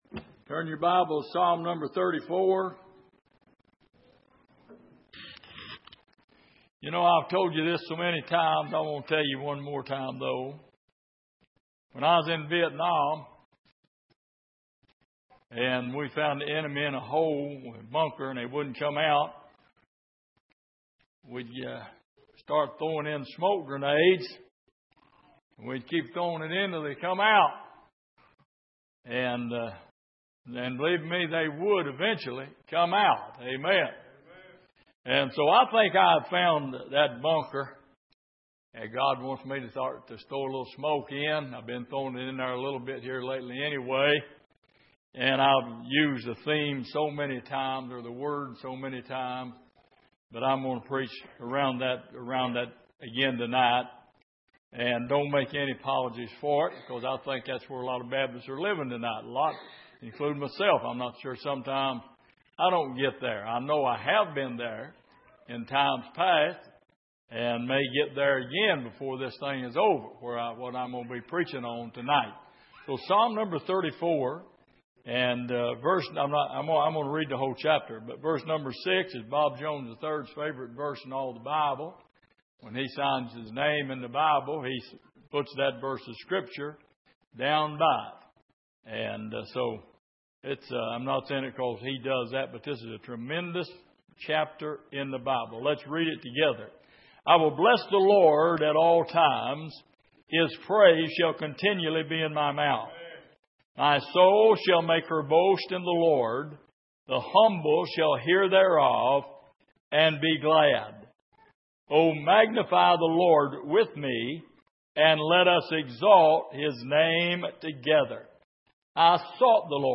Passage: Psalm 34:6 Service: Midweek